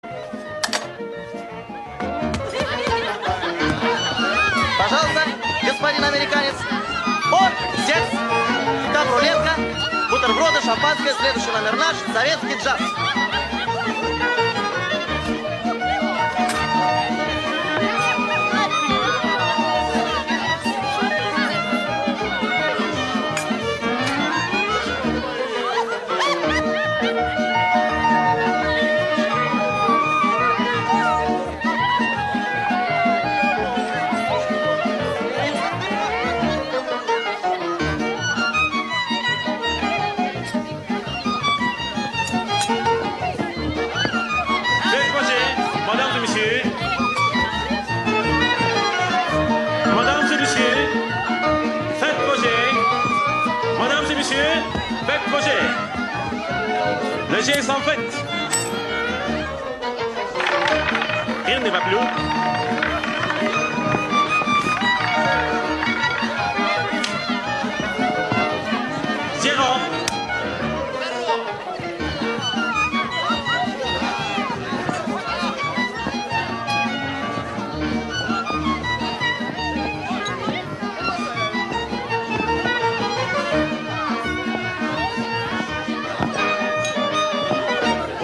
Хореография Кан-кана, хотя размер чардаша.